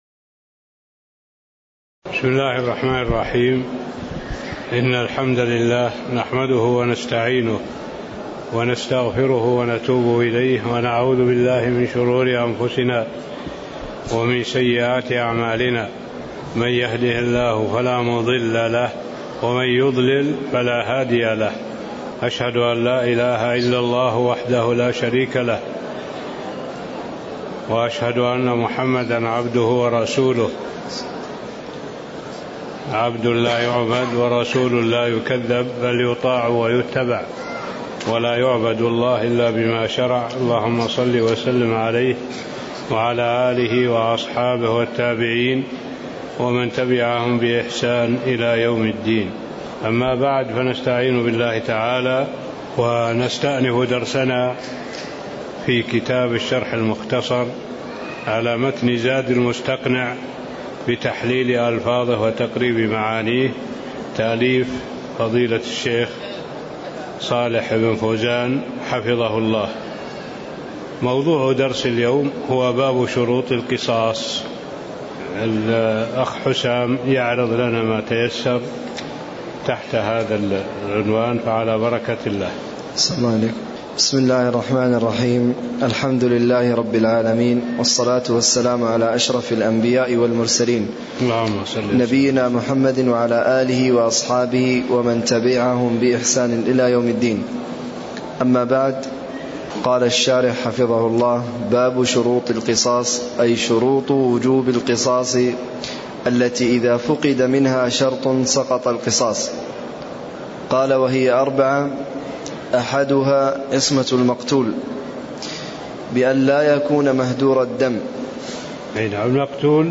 تاريخ النشر ١٣ شوال ١٤٣٥ هـ المكان: المسجد النبوي الشيخ: معالي الشيخ الدكتور صالح بن عبد الله العبود معالي الشيخ الدكتور صالح بن عبد الله العبود باب شروط القصاص (02)